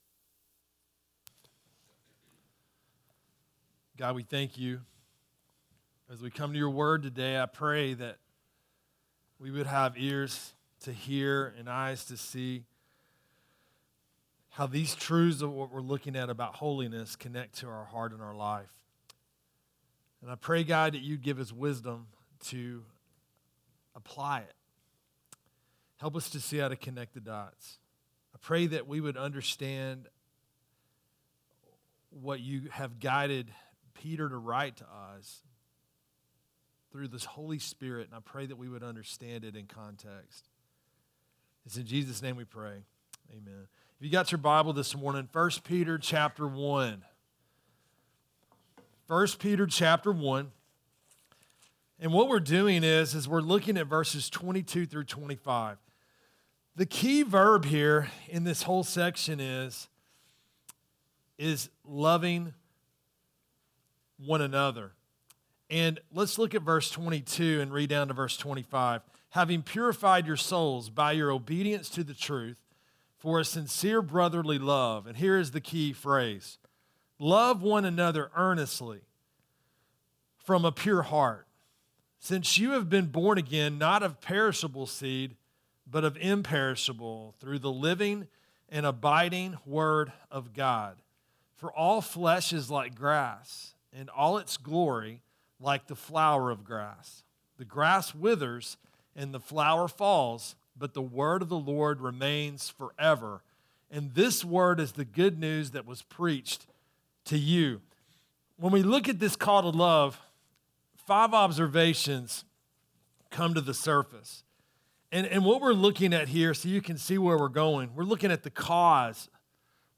Sermons
Sermons from Riverside Community Church: Scottsboro, AL